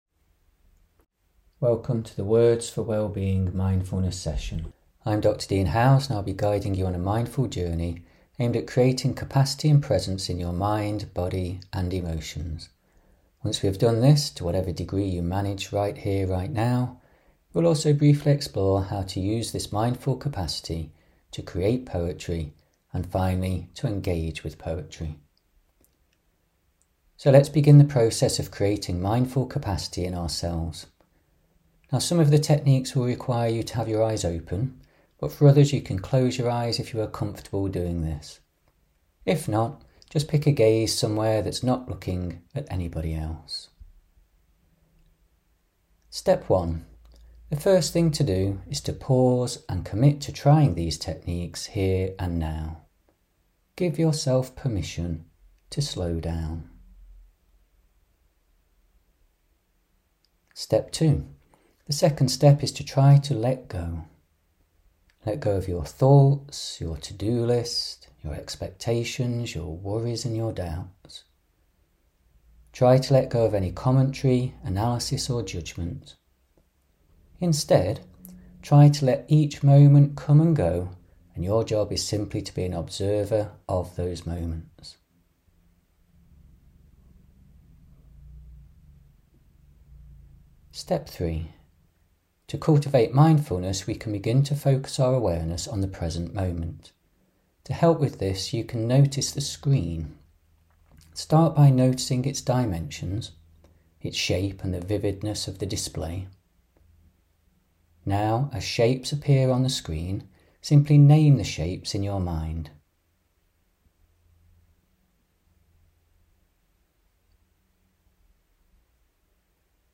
words_for_wellbeing_mindfulness_audio.mp3